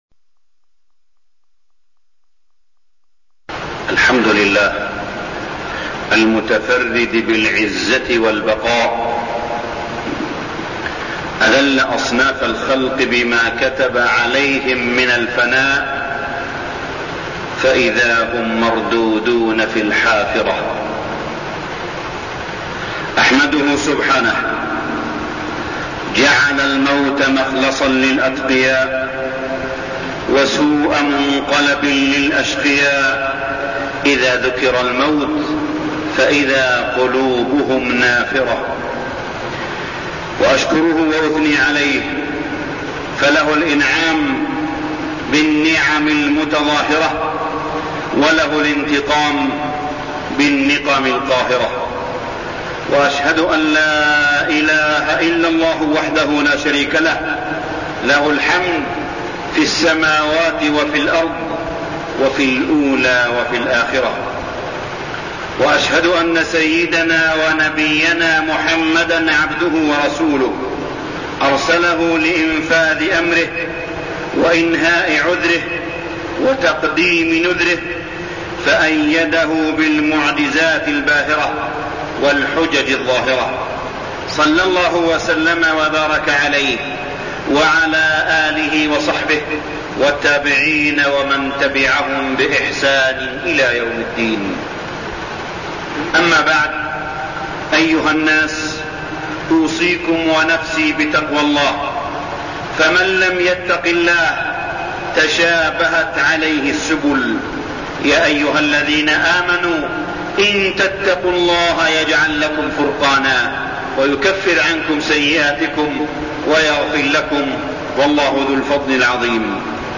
تاريخ النشر ٢٥ صفر ١٤١٤ هـ المكان: المسجد الحرام الشيخ: معالي الشيخ أ.د. صالح بن عبدالله بن حميد معالي الشيخ أ.د. صالح بن عبدالله بن حميد التزود إلى الآخرة